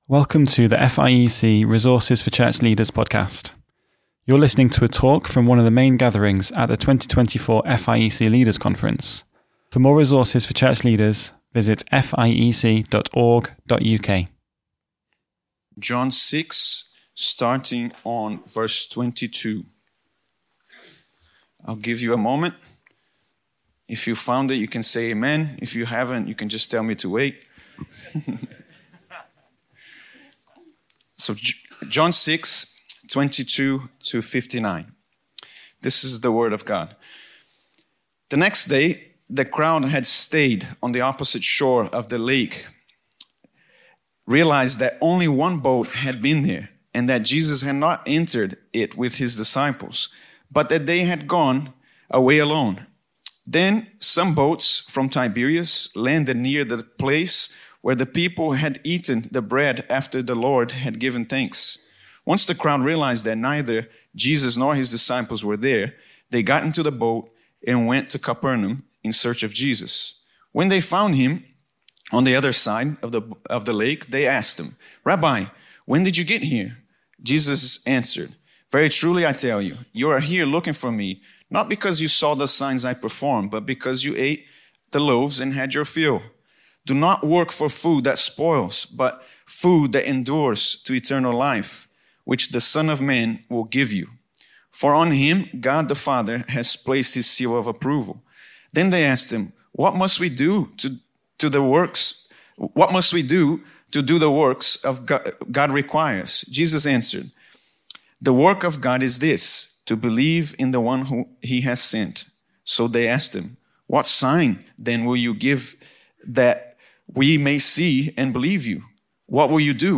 I-Am-the-Bread-of-Life-FIEC-Leaders-Conference-2024.mp3